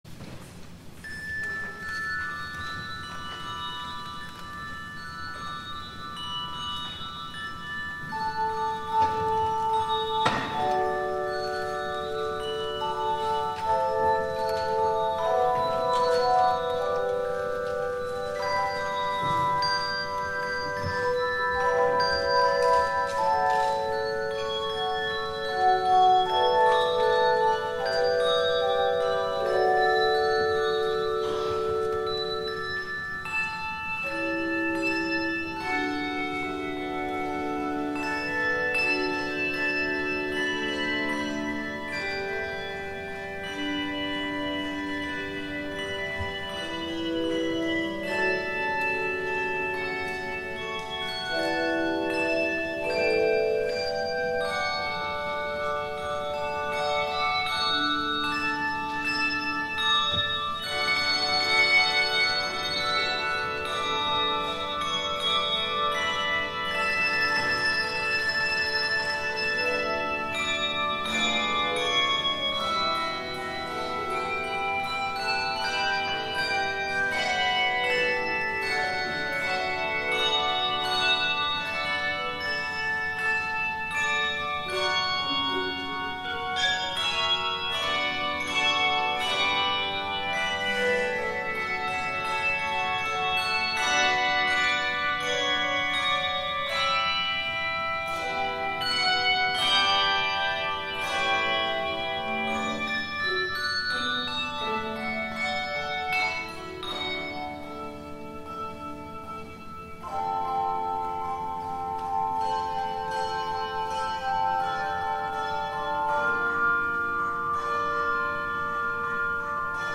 THE OFFERTORY